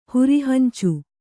♪ huri hancu